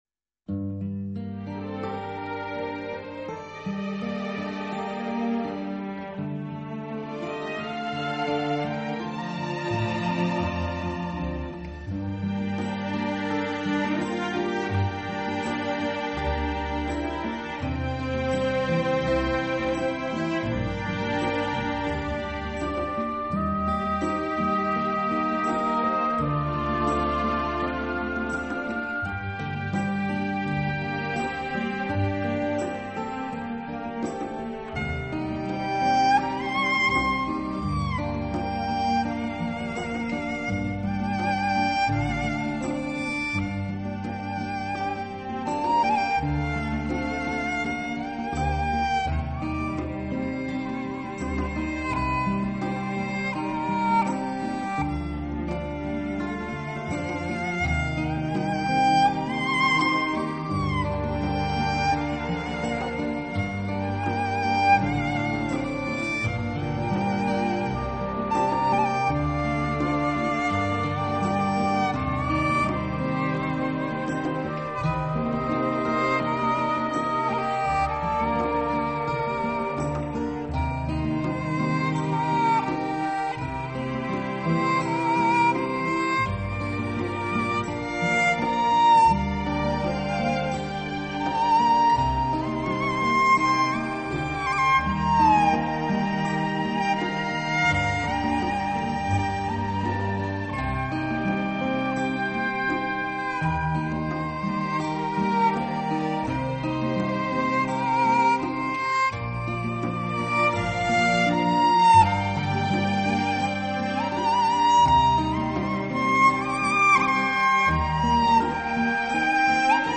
胡琴演奏
音乐类型:  Newage
胡琴的爱.恨.情.仇.喜.怒.哀.乐
板胡演奏